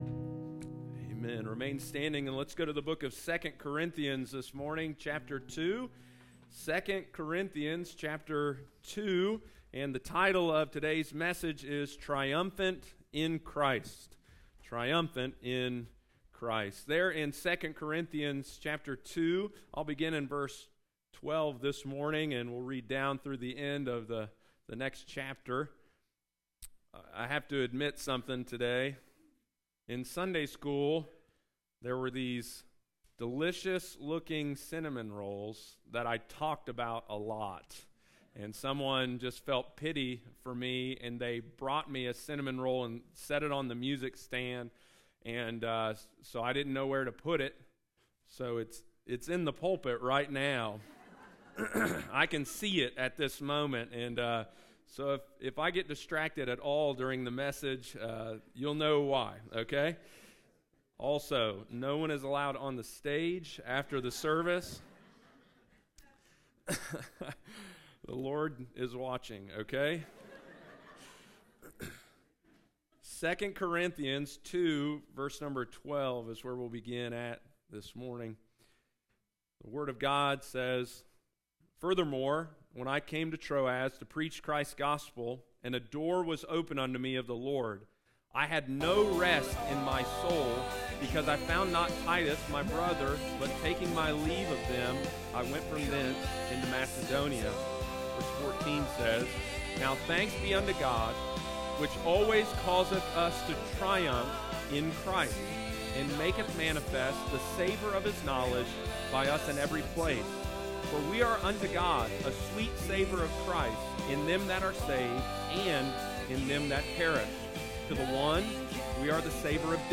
continues the series on “II Corinthians ” on Sunday morning, May 19, 2024.